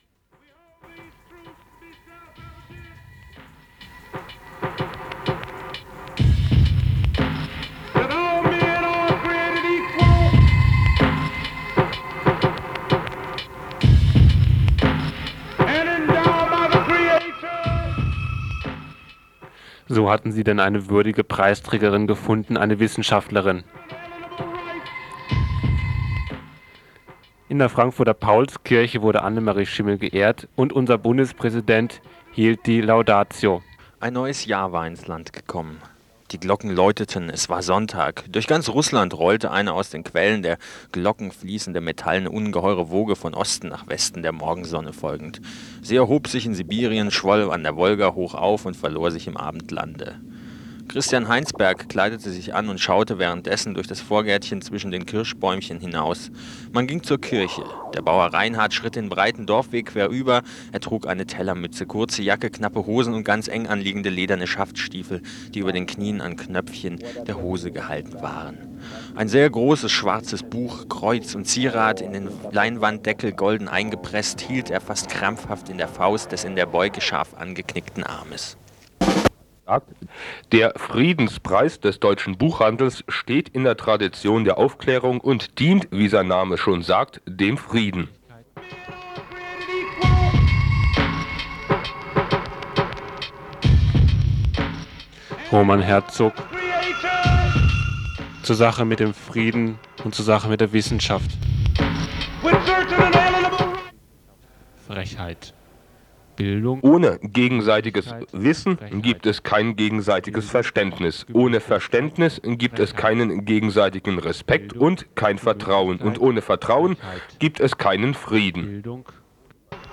Roman Herzogs Laudatio auf Annemarie Schimmel